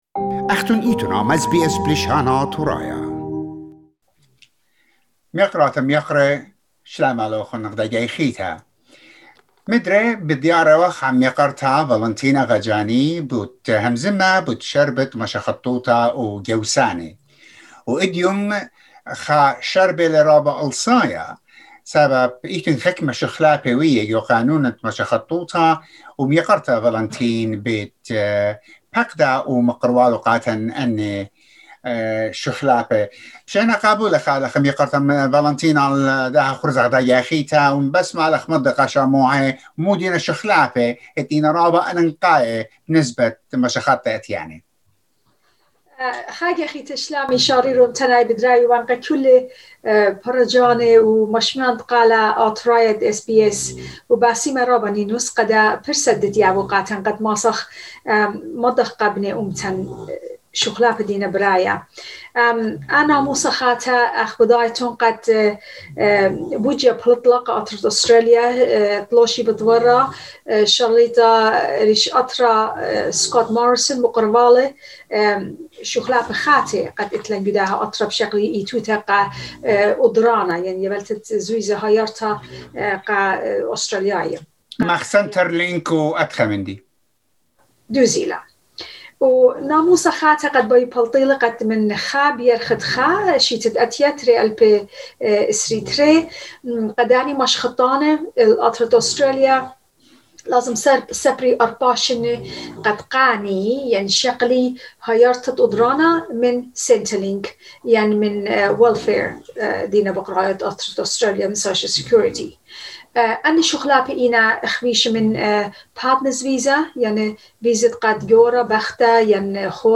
SBS Assyrian